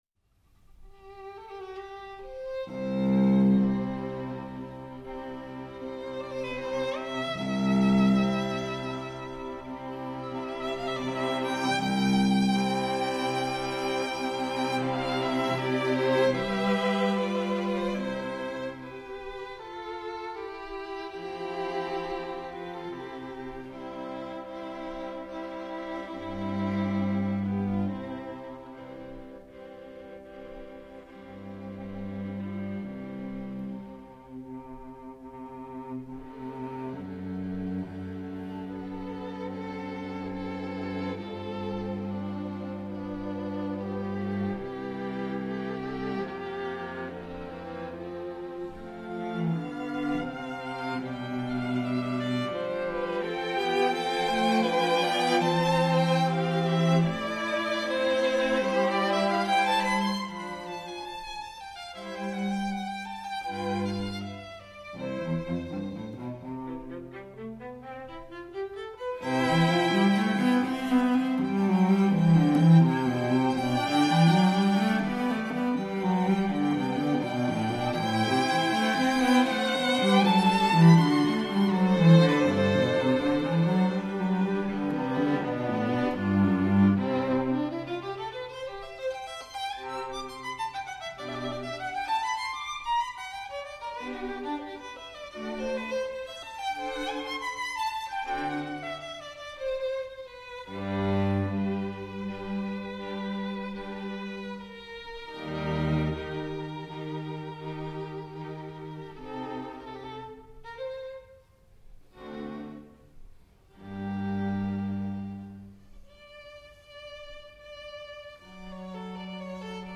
String Quartet in G major
Andante cantabile